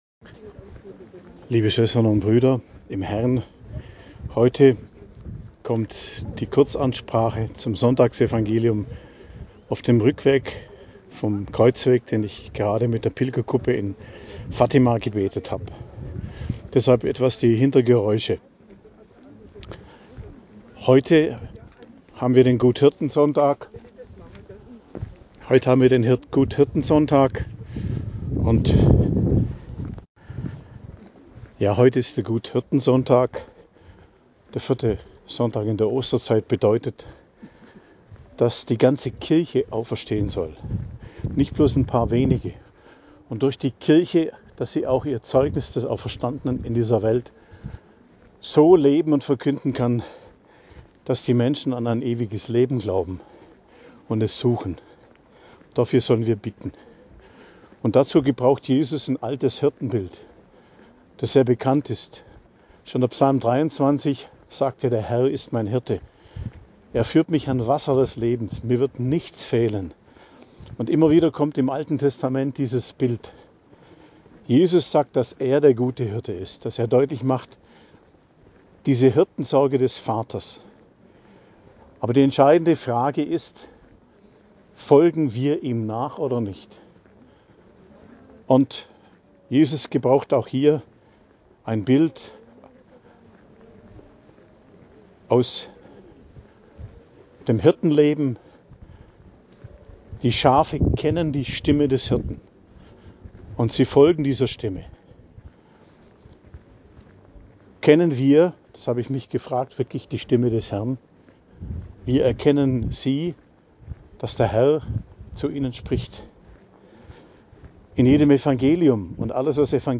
Predigt zum 4. Sonntag der Osterzeit, 11.05.2025